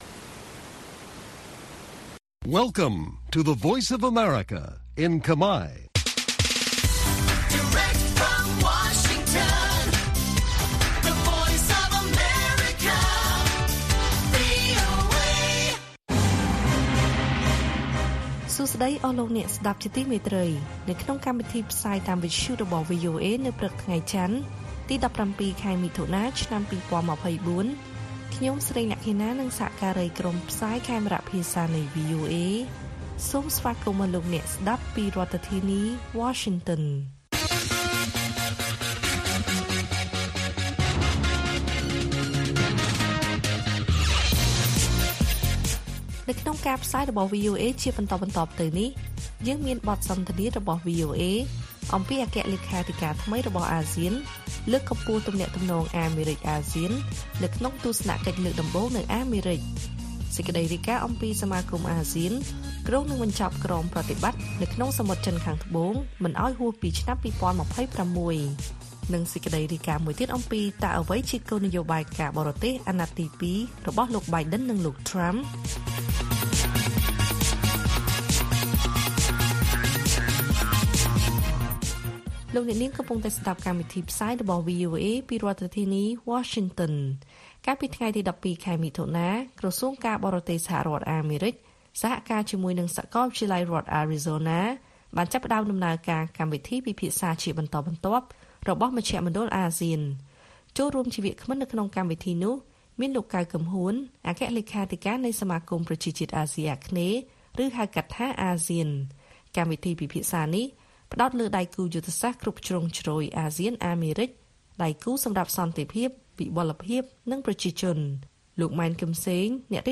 ព័ត៌មានពេលព្រឹក ១៧ មិថុនា៖ អគ្គលេខាធិការថ្មីរបស់អាស៊ានលើកកម្ពស់ទំនាក់ទំនងអាមេរិកអាស៊ានក្នុងទស្សនកិច្ចលើកដំបូងនៅអាមេរិក
ព័ត៌មាននៅថ្ងៃនេះមានដូចជា កិច្ចសន្ទនាវីអូអេអំពីអគ្គលេខាធិការថ្មីរបស់អាស៊ានលើកកម្ពស់ទំនាក់ទំនងអាមេរិកអាស៊ានក្នុងទស្សនកិច្ចលើកដំបូងនៅអាមេរិក។ សមាគមអាស៊ានគ្រោងនឹងបញ្ចប់ក្រមប្រតិបត្តិក្នុងសមុទ្រចិនខាងត្បូងមិនឱ្យហួសពីឆ្នាំ២០២៦ និងព័ត៌មានផ្សេងៗទៀត៕